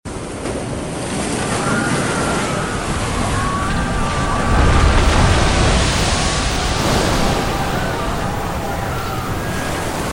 山洪拍打建筑，建筑瞬间坍塌！